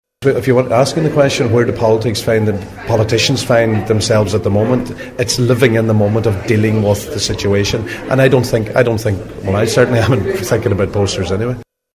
But Government Chief Whip Joe McHugh says its not something on his mind: